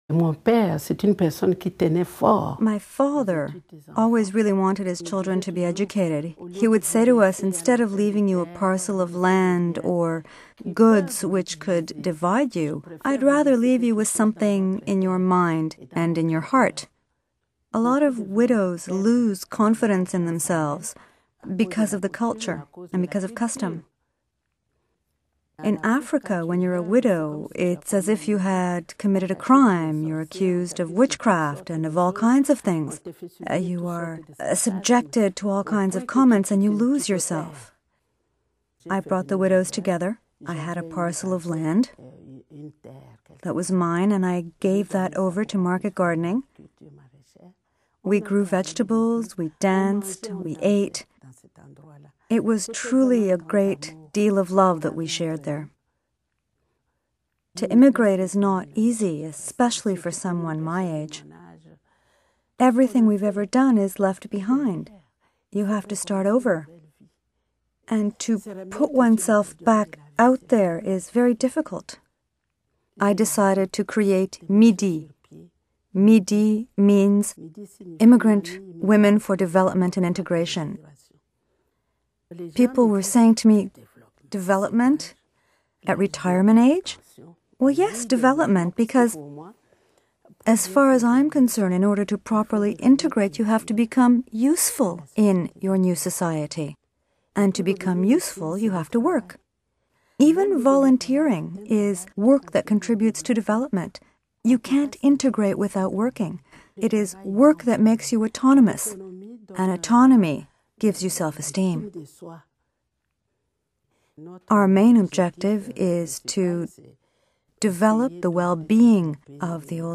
Remarks recorded during an interview.